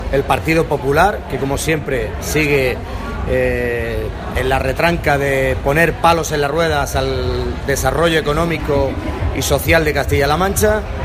El parlamentario regional ha realizado estas declaraciones en un encuentro con los medios de comunicación que ha tenido lugar en el stand del PSOE, en la Feria de Albacete.
Cortes de audio de la rueda de prensa